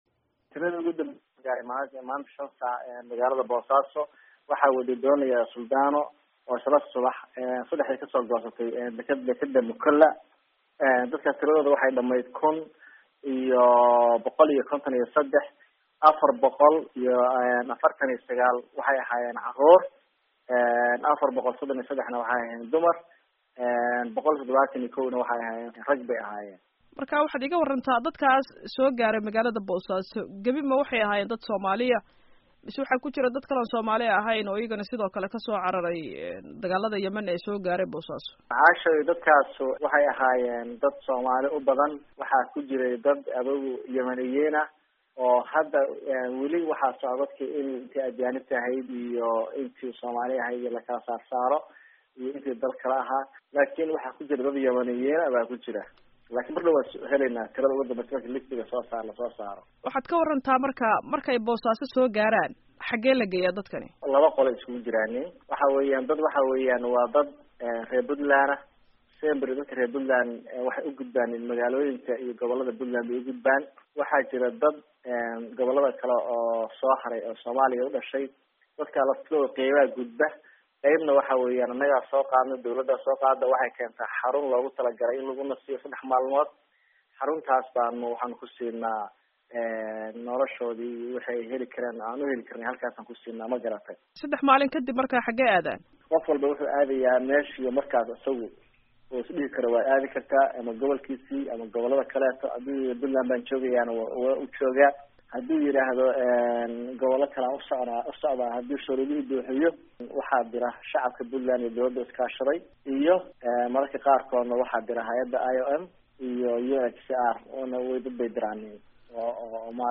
C/laahi Xaashi "Qoobdeero" oo ah wasiir-ku-xigeenka arrimaha gudaha Puntland, madaxna u ah guddiga maamulka Puntland uu u xilsaaray dib u dejinta qaxootiga Yemen ka soo qaxaya ayaa VOA u sheegay in qaxootiga ay u baahan yihiin in gacan loo fidiyo.